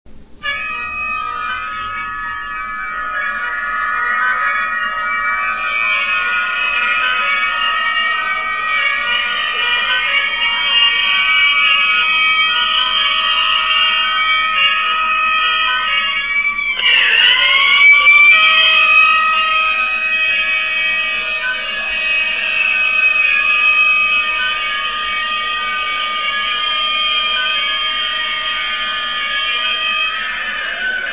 Ambiance pendant les lancers francs de l'équipe adverse 122 ko